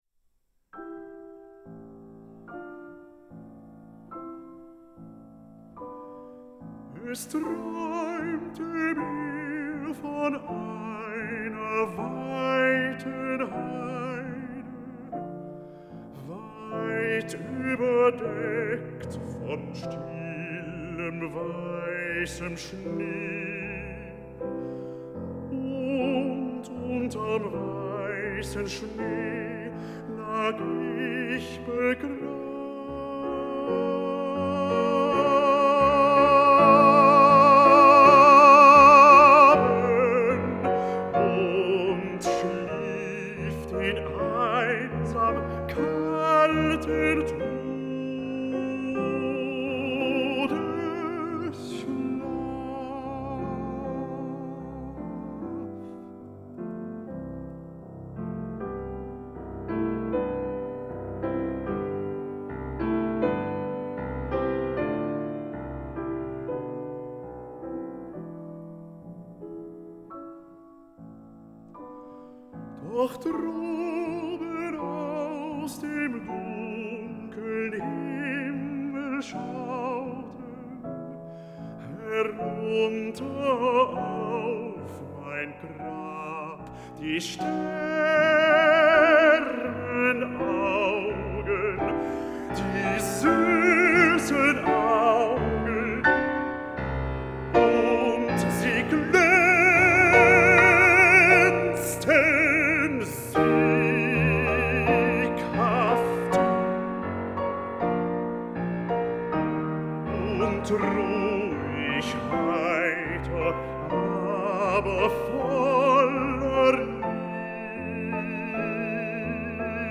baritone
pianist